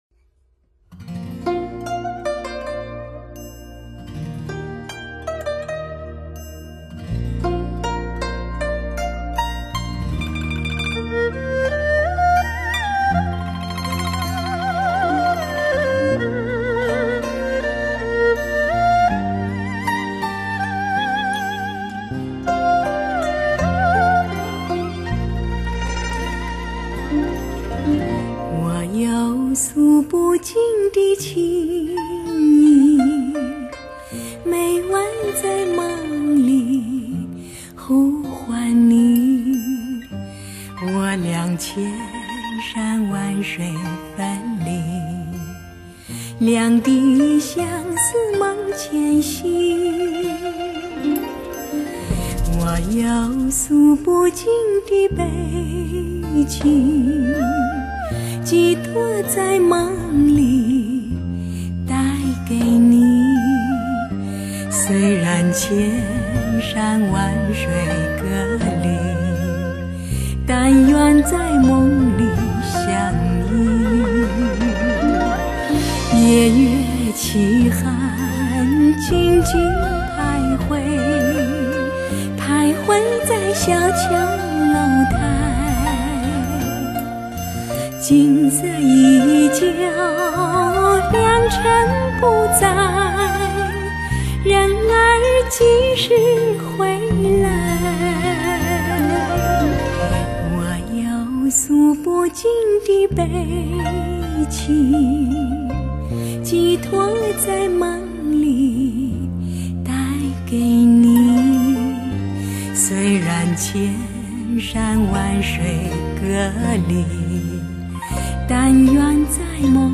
曲风加入了更多清新时尚元素，定能给听觉带来新鲜与喜悦！